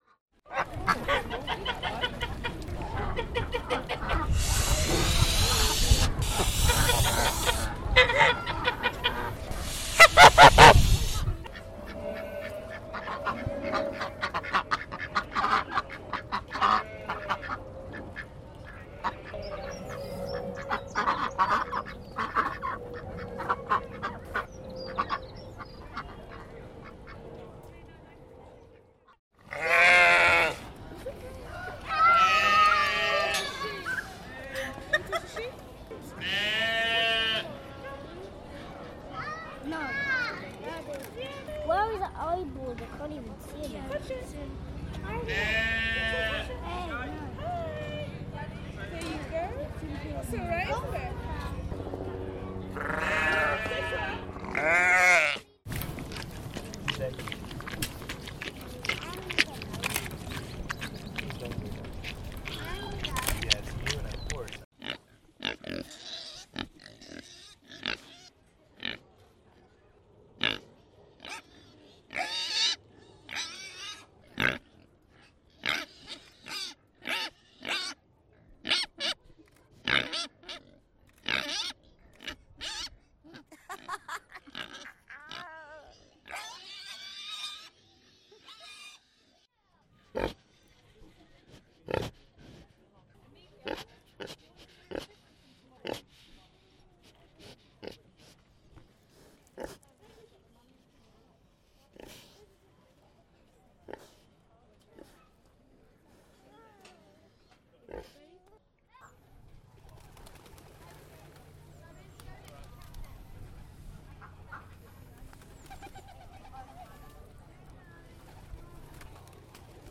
Surrey Docks City Farm on the Thames cycle path. A sound scape.
Sounds of the city farm. Starts with Swans hissing, ducks quacking. Goats and sheep baaing, people laughing. The sound of a police helicopter, A horse licking a hand. Piglets squealing. A Sow breathing heavily and snorting. The sounds of the birds fluttering their wings at the end of the day.